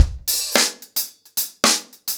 DaveAndMe-110BPM.23.wav